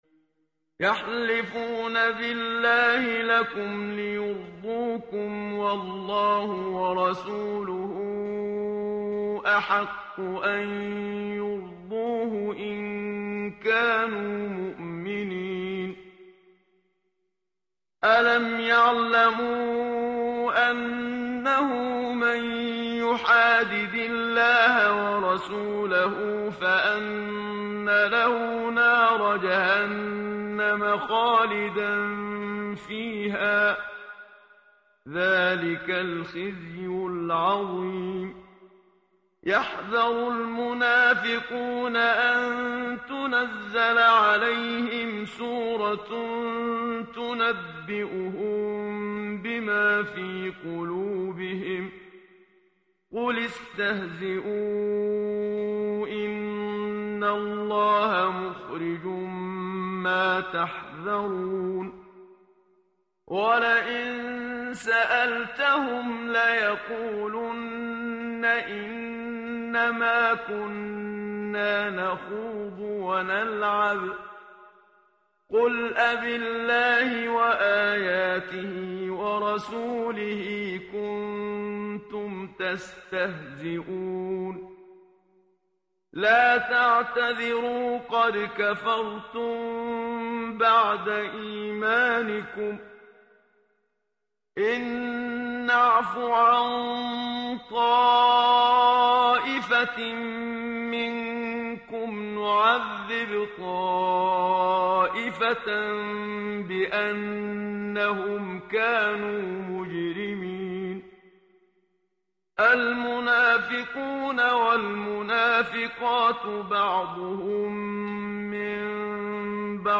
برای نورانیت قلوب هر روز یک صفحه از آیات قرآن را هم صدا با استاد منشاوی و با ترجمه آیت الله "مکارم شیرازی" می خوانیم.